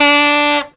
HONK.wav